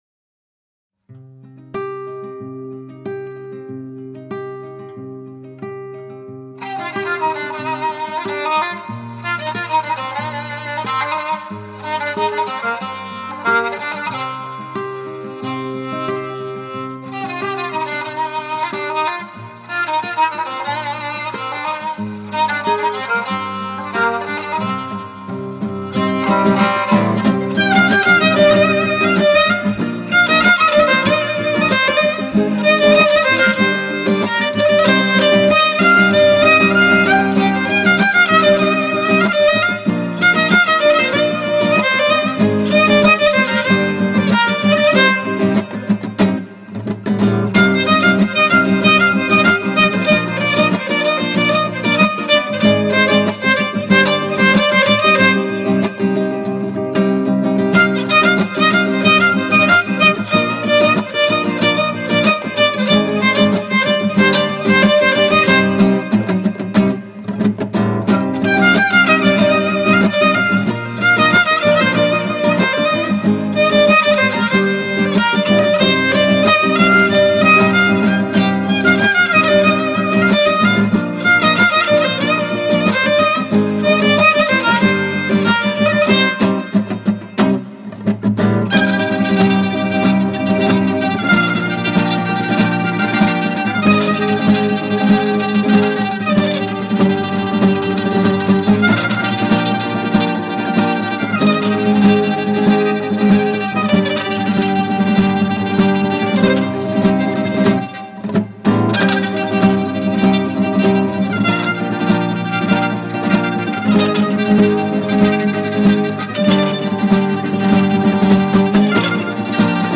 یک قطعه موسیقی بی‌کلام
کمانچه
گیتار